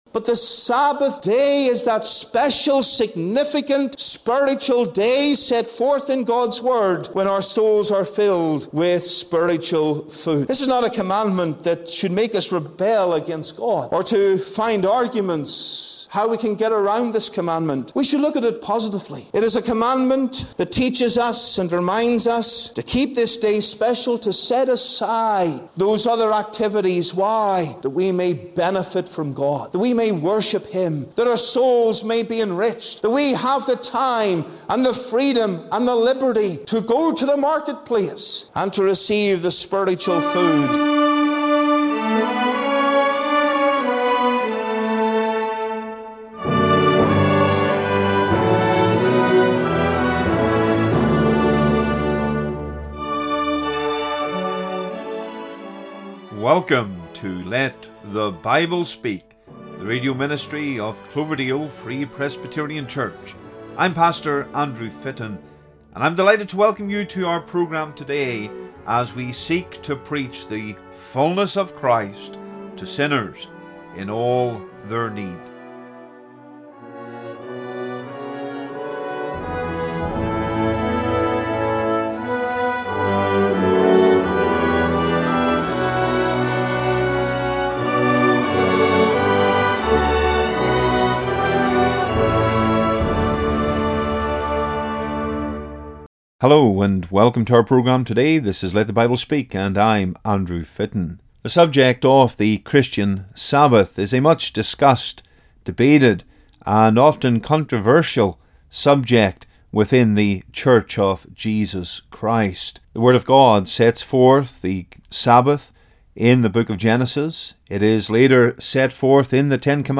Sermons | The Free Presbyterian Church in Cloverdale